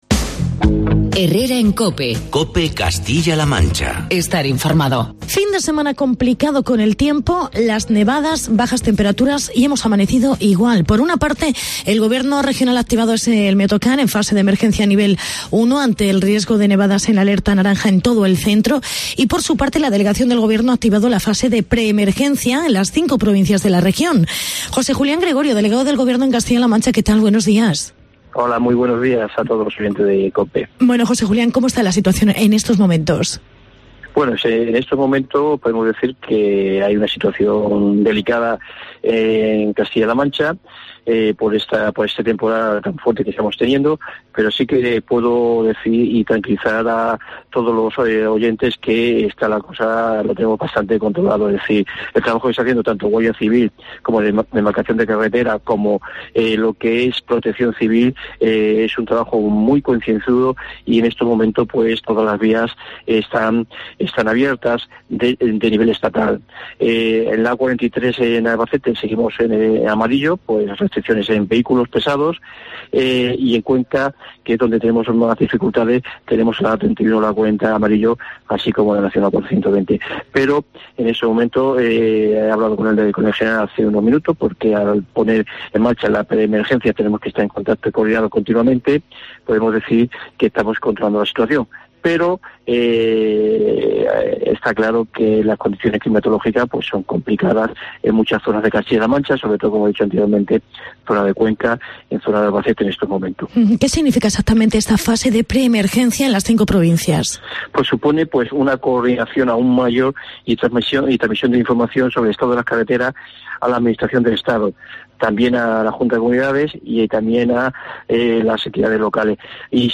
Entrevista a José Julián Gregorio. Delegao del Gobierno CLM